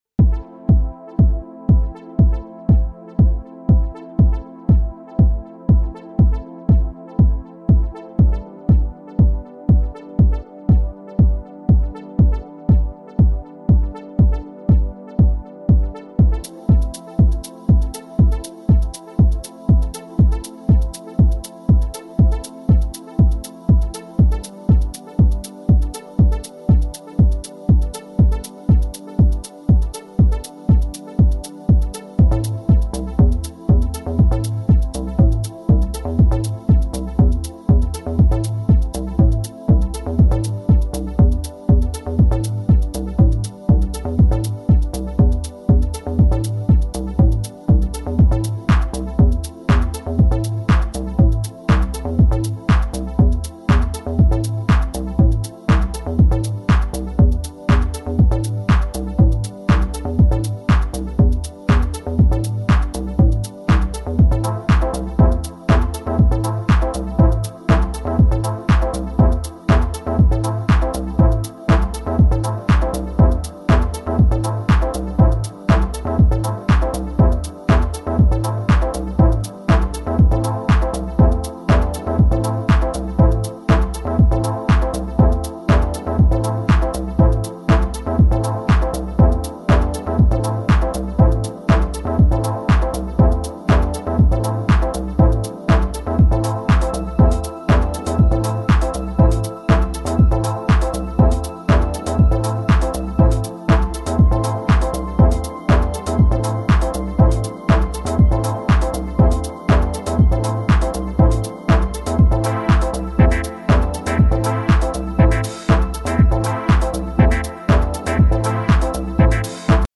Deeper Chicago orientated house tracks.